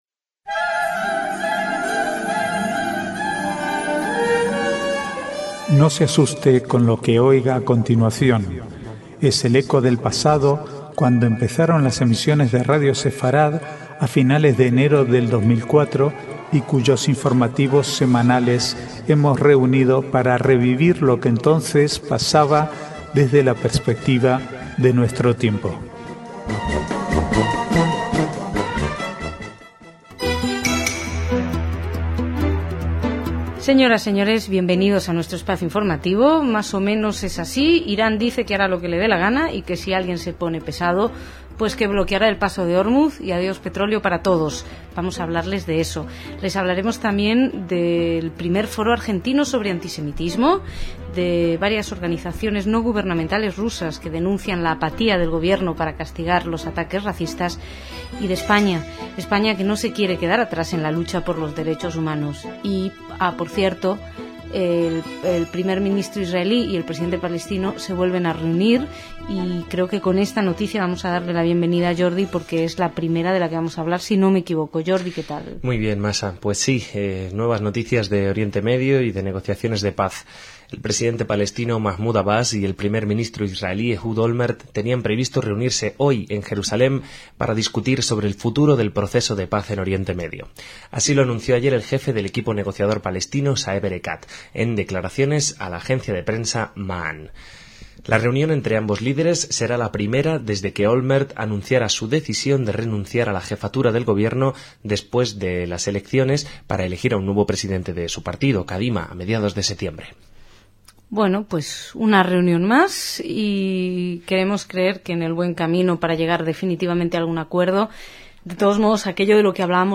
Archivo de noticias del 6 al 8/8/2008